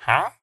haggle1.ogg